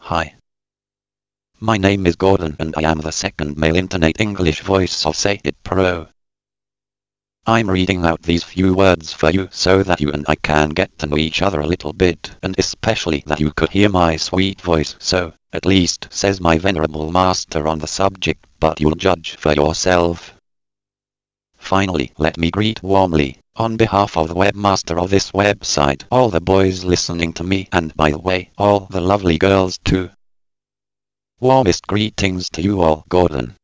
Texte de démonstration lu par Gordon, deuxième voix masculine anglaise de LogiSys SayItPro (Version 1.70)